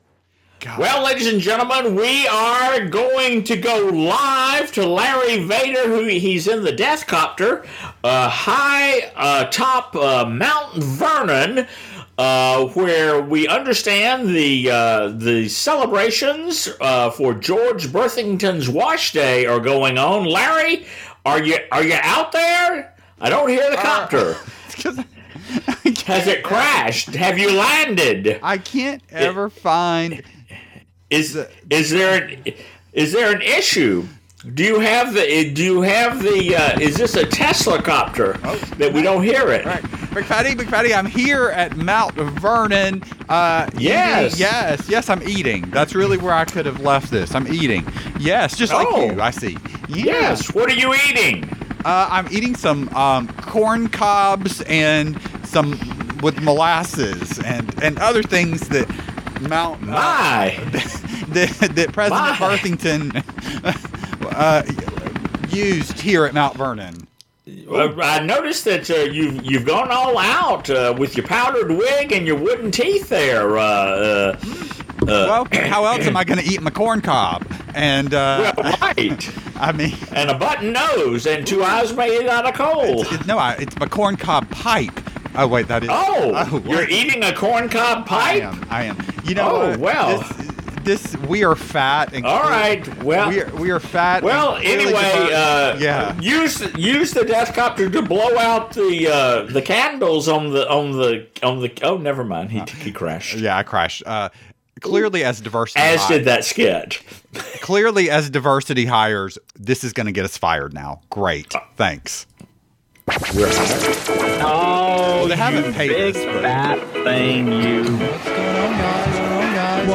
Also, there’s a new jalopy sound.